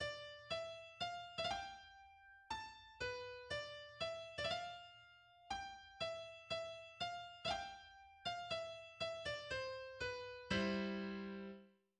Genre Symphonie
3. Menuetto et trio, en ut majeur (trio en fa majeur), à
Première reprise du Menuetto :